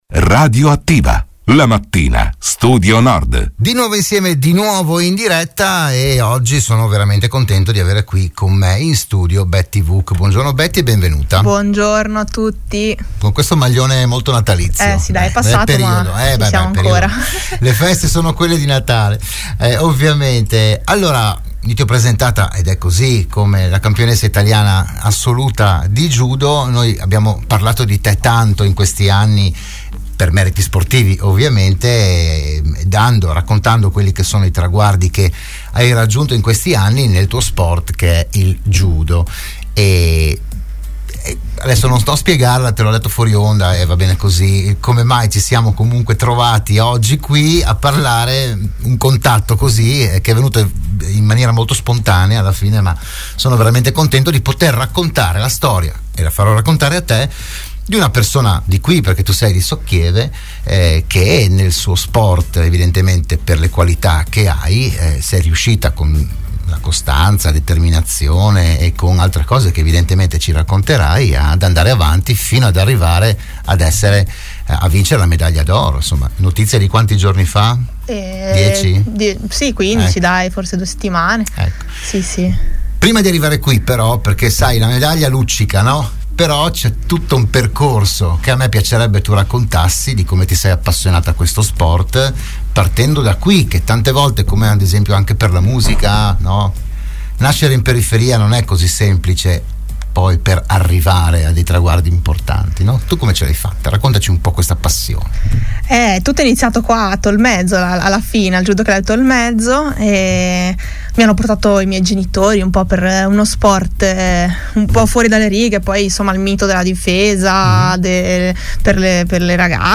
RadioAttiva“, la trasmissione del mattino di Radio Studio Nord